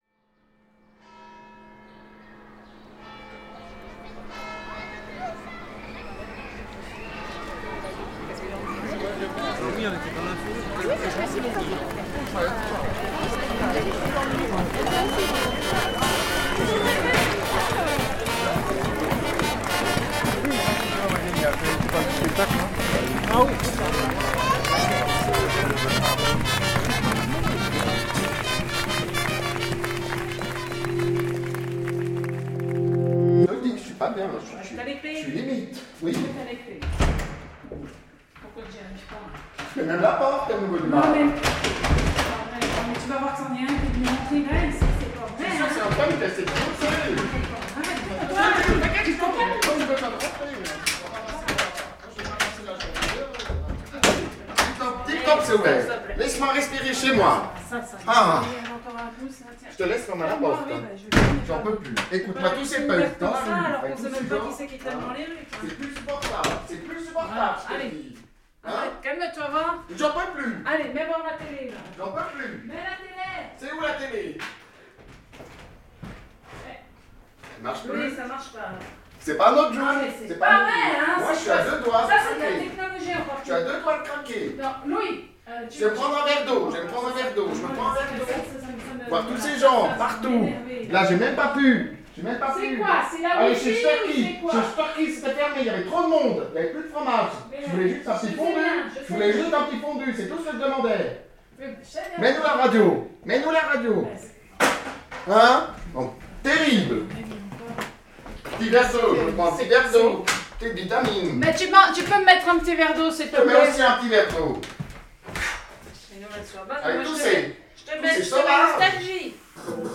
Josie et Louis n'en peuvent plus, ils sont agoraphobes et voient la plage comme un rassemblement punk alors ils n'ont d'autre choix que de rentrer chez eux. Une petite fiction radiophonique réalisée en deux heures, deux prises, deux improvisateurs, sans prétention, juste pour rire.